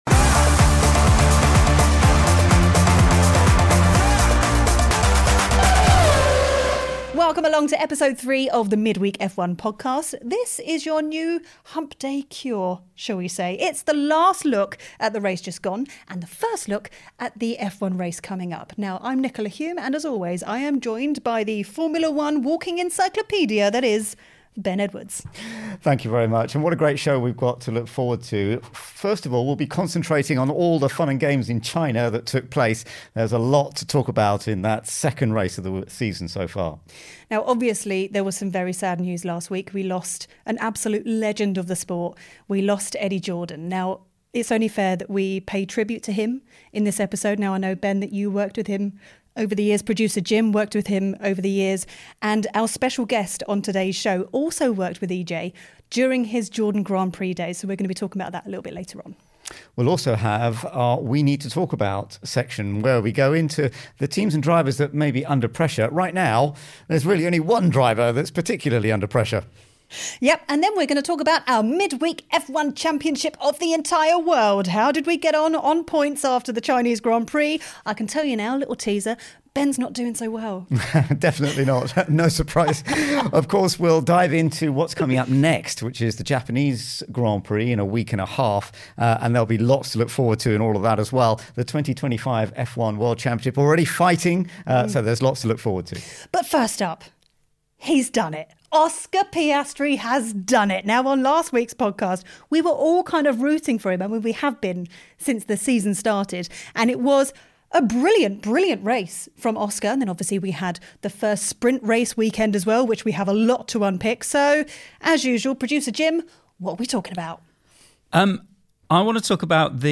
1 1994 Italian GP *Round 12* (Eurosport Commentary) 1:34:43